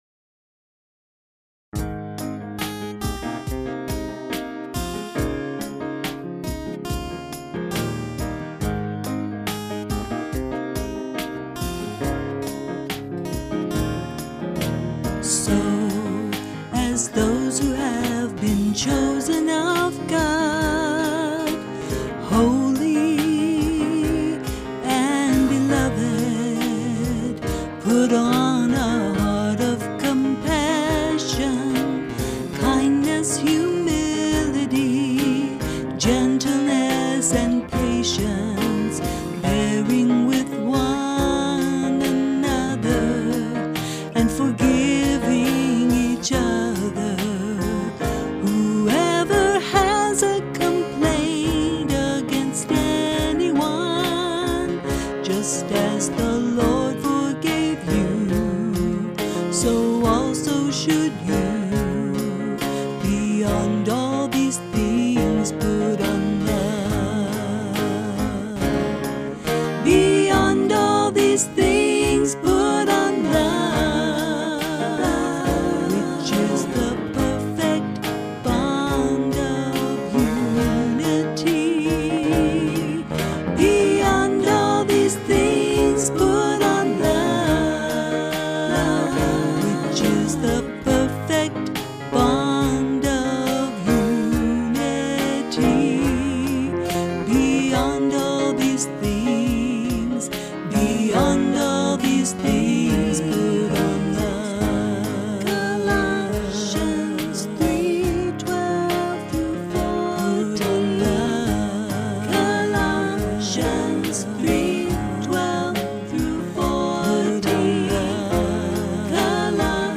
With Vocals |